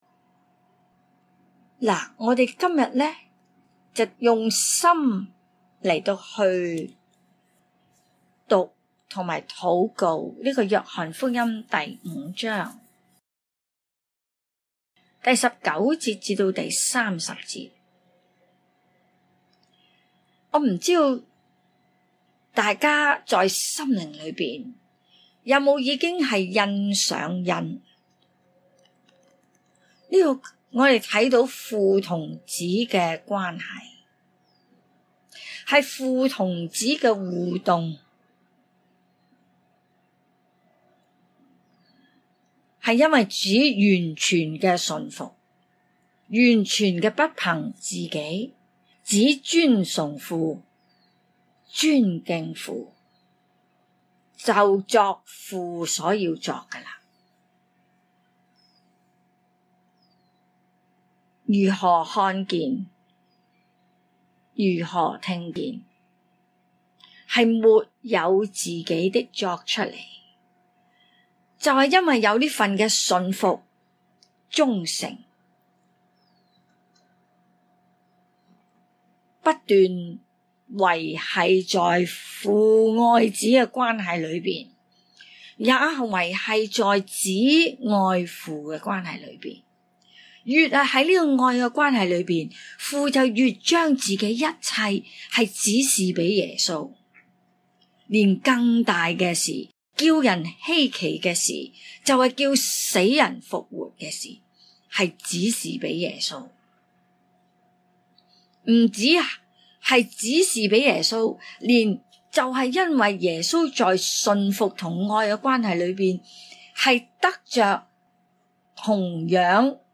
請注意： 每段教導均以認識【上帝之所是】和貫穿新舊約的重點信息－【上帝全備救贖計劃】如何安排的心意，作為明白該段經文的基礎； 若在學習中，要得到更好的果效，請在聆聽每段教導前，先【用心讀】該段經文最少兩次，然後專注在心靈裡來聆聽數次； 每段聲帶均按經文的章節次序來教導，故有些內容並不是局限於一個重點或主題來闡明； 每段教導的「聲帶」均為現場錄音，就部份或有雜音及音效未盡完善的地方，敬請包容！